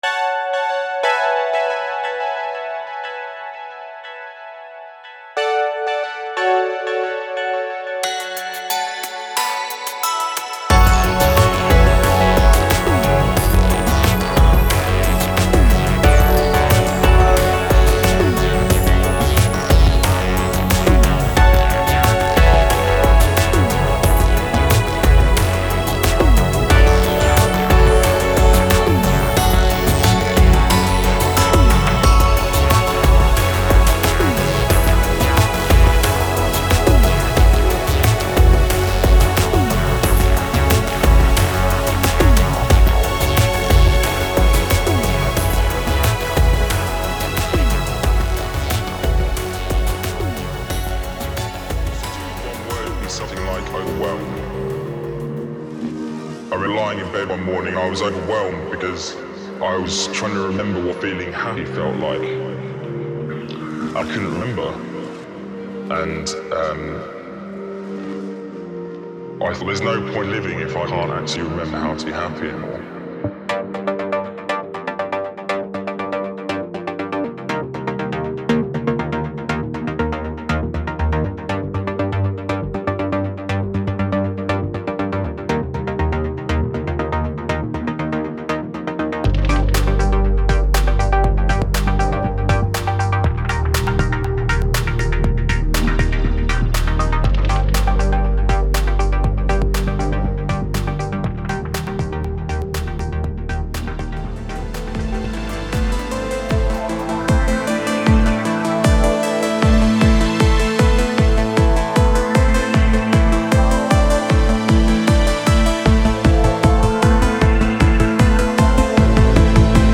rhythmic, melodic and sophisticated electronic albums
THIS TIME I USED THE FOLLOWING SYNTHS AND FX:
No AI was used to produce this album.